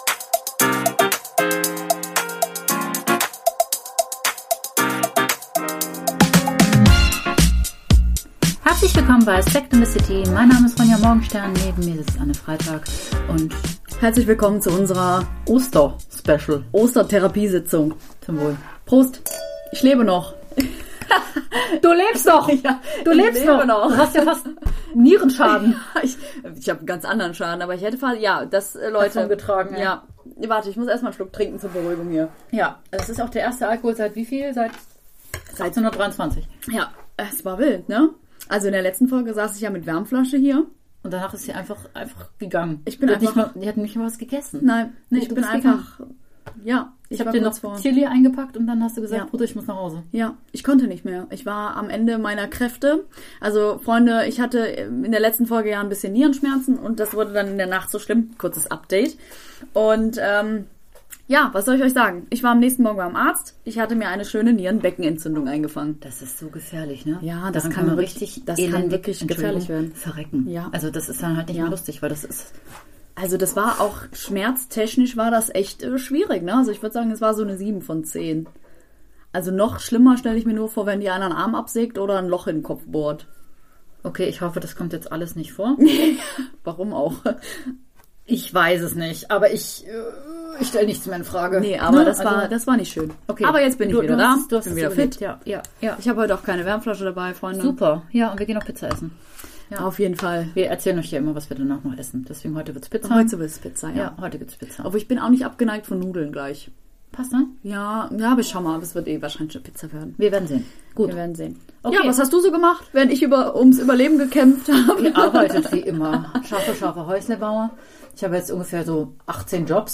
Dieses Mal wird es tiefgründig im Kölner Souterrain Studio. Welche Charakterzüge sind einem eigentlich angeboren und welche werden durch die Gesellschaft geprägt? Und wie findet man sich eigentlich selbst?